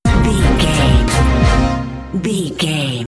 Fast paced
In-crescendo
Aeolian/Minor
B♭
strings
drums
horns